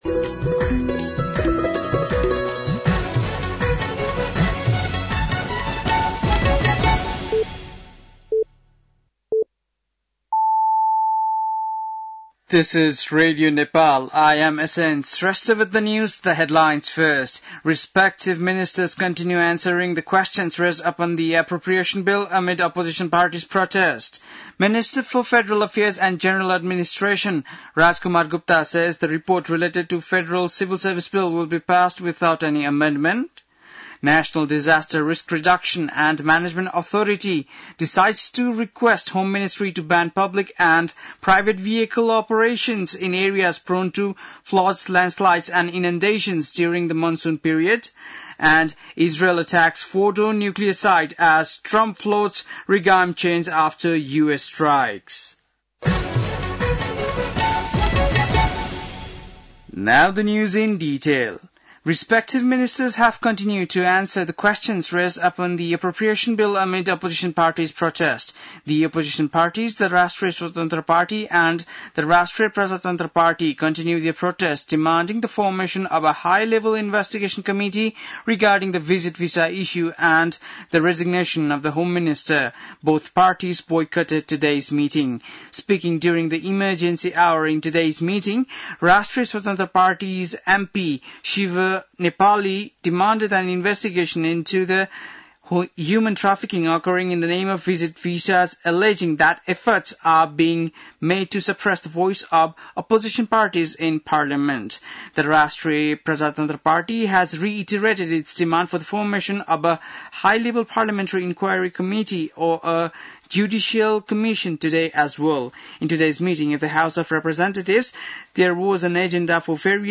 An online outlet of Nepal's national radio broadcaster
बेलुकी ८ बजेको अङ्ग्रेजी समाचार : ९ असार , २०८२
8-pm-english-news-3-09-.mp3